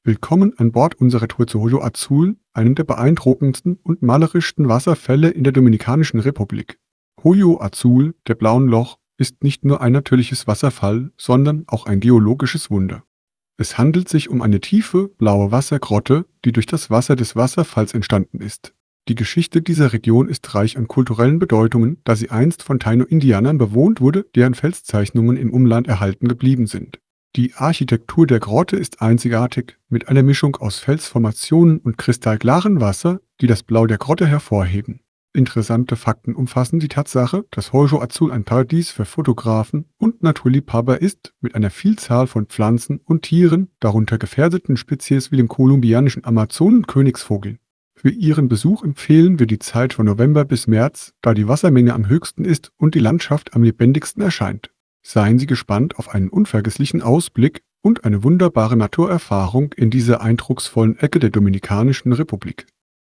karibeo_api / tts / cache / 662bed8f34d9c8db3362a5fbaf49db1f.wav